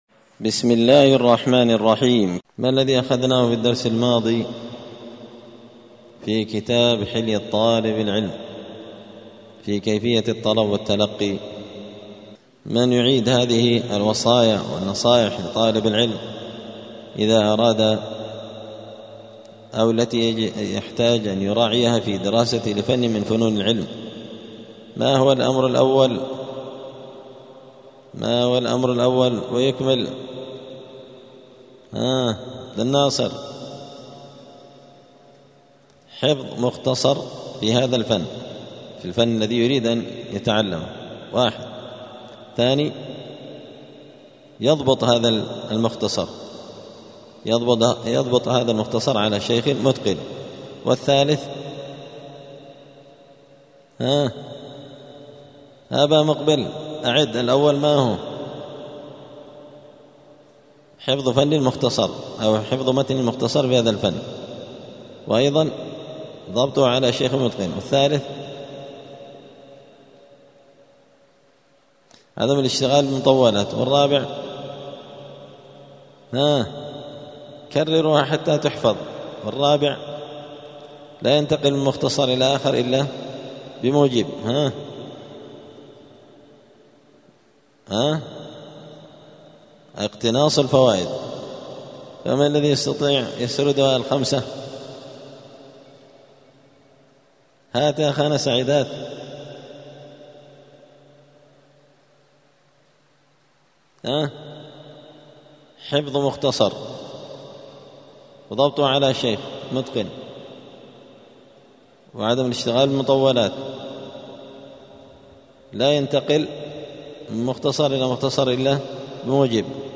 *الدرس التاسع عشر (19) {فصل كيفية الطلب ومراتبه لا تنتقل من مختصر إلى آخر بغير موجب}*
الأربعاء 7 محرم 1447 هــــ | الدروس، حلية طالب العلم، دروس الآداب | شارك بتعليقك | 6 المشاهدات